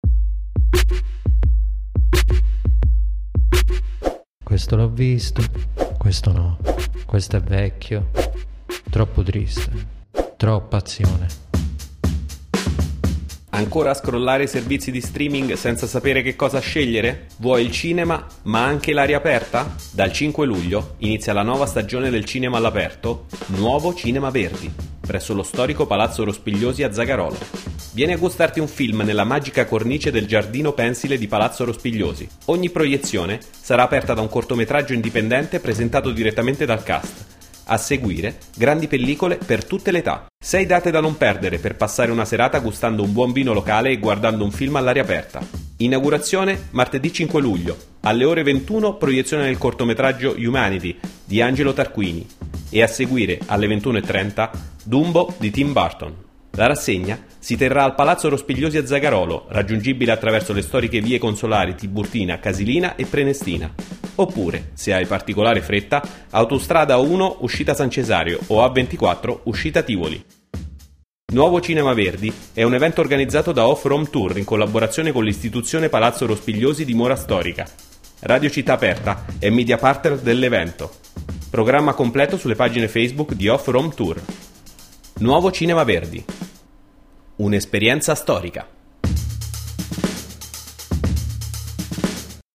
spot-offrome-cinema.mp3